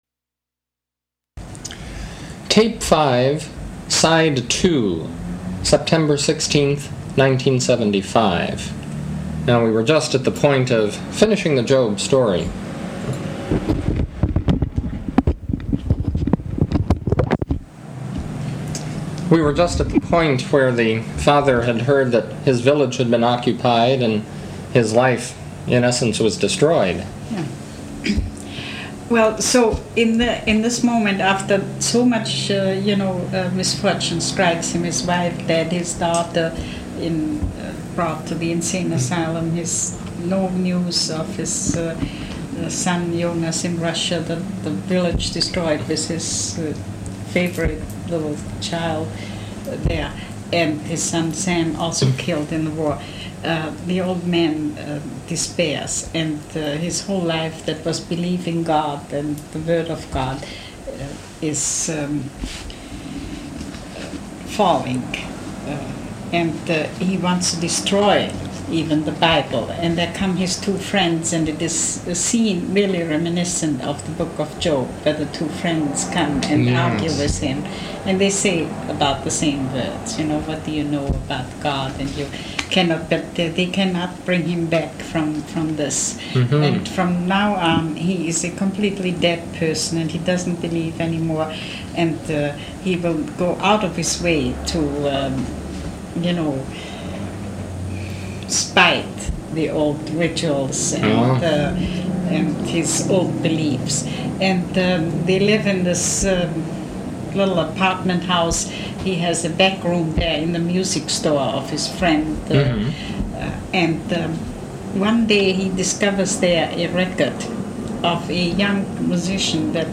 Interviews-Lectures